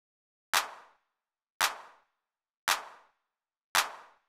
06 Clap.wav